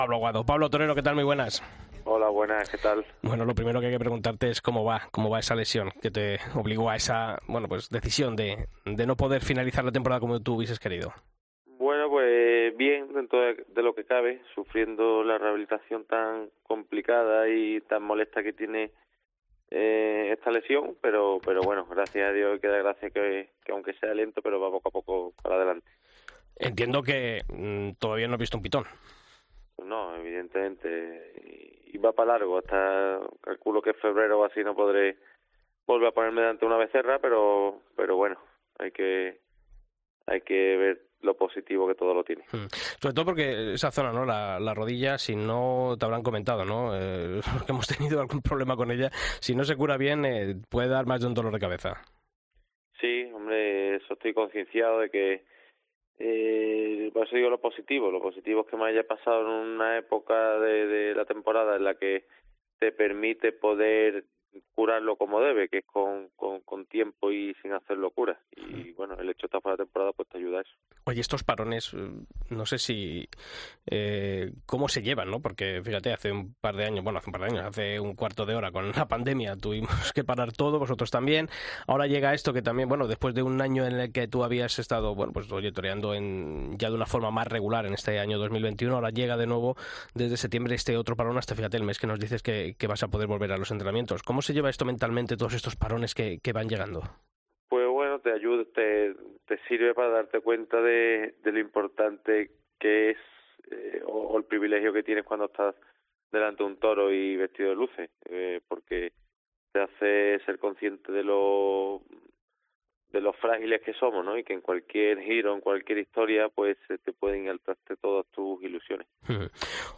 Escucha la entrevista a Pablo Aguado en El Albero